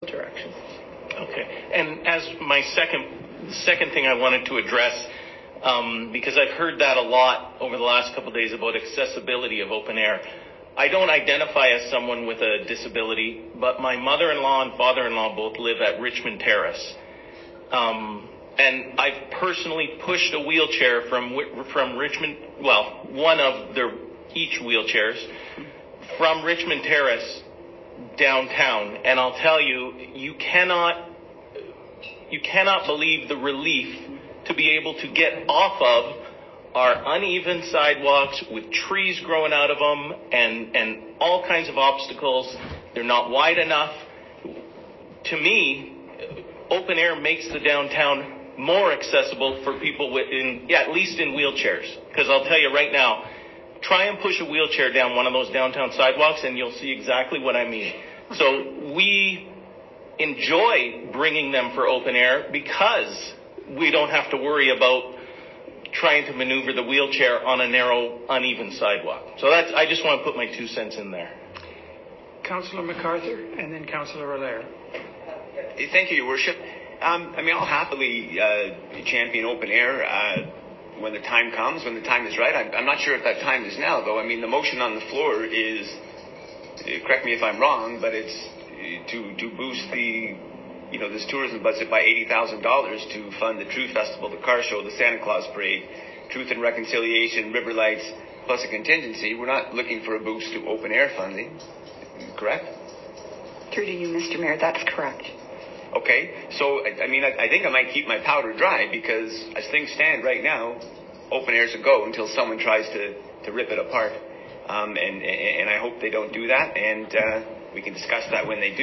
the audio of a portion of the February 22, 2023 meeting and a portion of the discussion about accessibility barriers during the closed streets of Open Air; text is set out below.